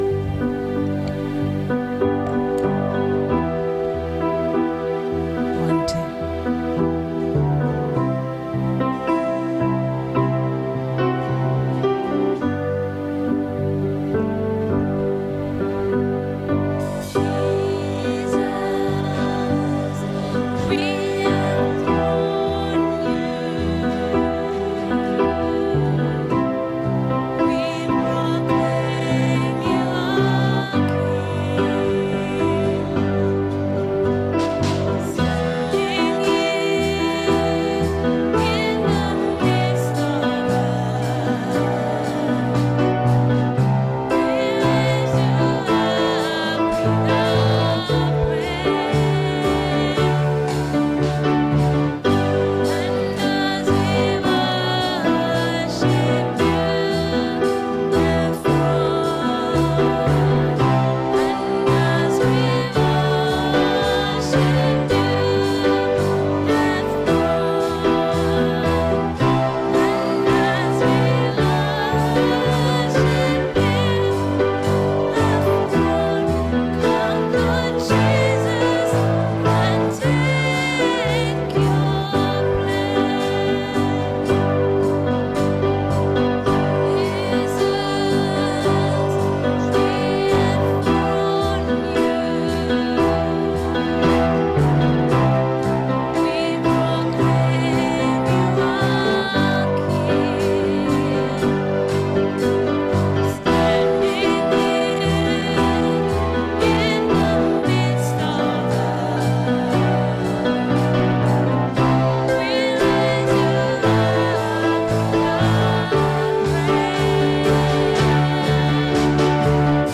Join us for the second in our sermon series on relationships. Today's service focuses on God-centred parenting and includes communion.
Service Audio